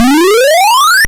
SMB1 Sounds